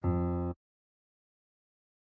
CHOMPStation2/sound/piano/E#3.ogg
E#3.ogg